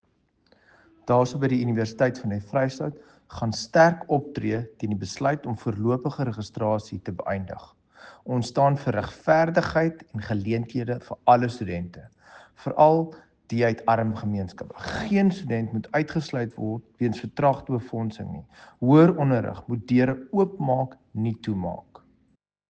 Afrikaans soundbite by Dr Igor Scheurkogel MP, and Sesotho soundbite by Jafta Mokoena MPL.